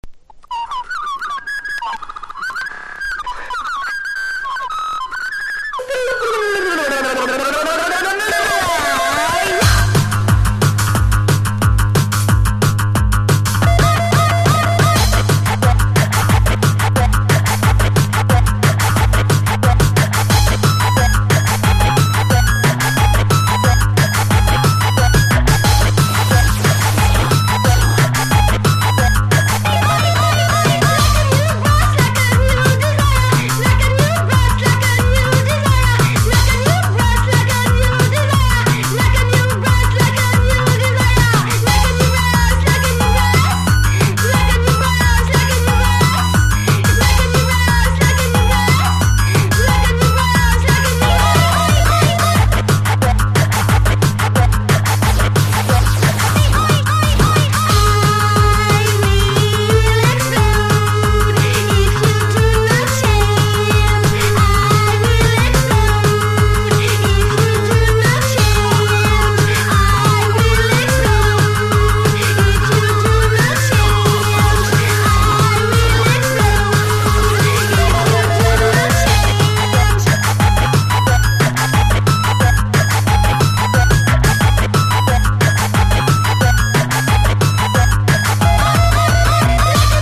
1. 00S ROCK >
シンセの効いたダークなメロディにキャッチーなボーカルのエレクトロ・パンク！！
ELECTRO